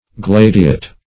Search Result for " gladiate" : The Collaborative International Dictionary of English v.0.48: Gladiate \Glad"i*ate\, a. [L. gladius sword.]